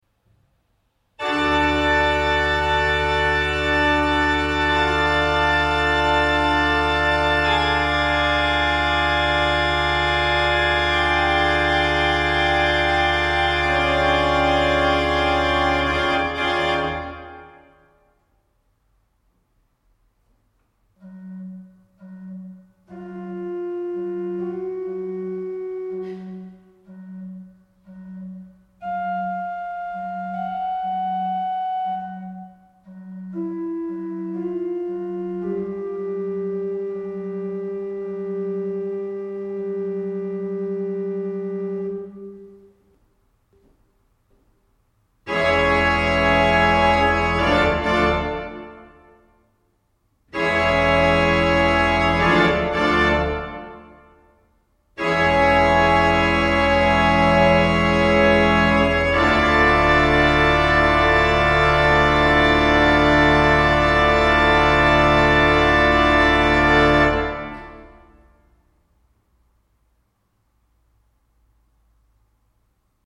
Gospel und Klassik: